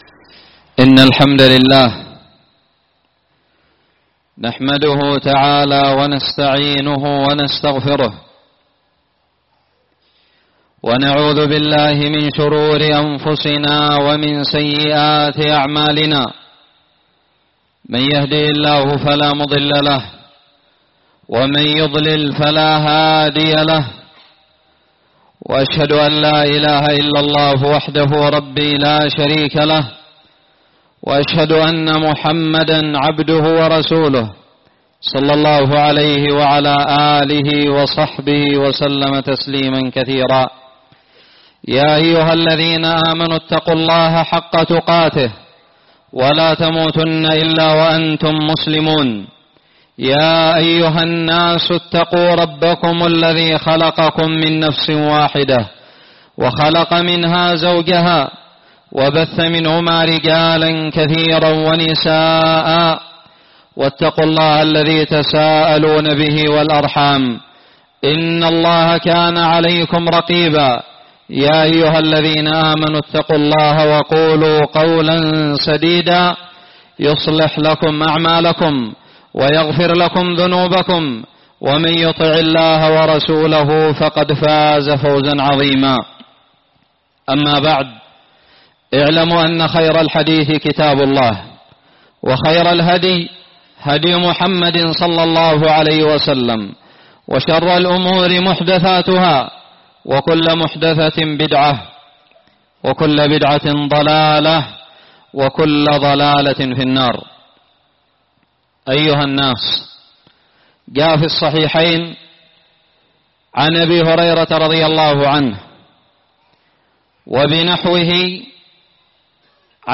خطب الجمعة
ألقيت بدار الحديث السلفية للعلوم الشرعية بالضالع في 11 محرم 1440هــ